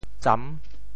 How to say the words 錾 in Teochew？
錾（鏨） Radical and Phonetic Radical 金 Total Number of Strokes 16 Number of Strokes 8 Mandarin Reading zàn TeoChew Phonetic TeoThew zam6 文 Chinese Definitions 錾 <名> 小凿,雕凿金石的工具 [chisel] 錾,小凿也。